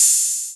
TM-88 Hat Open #06.wav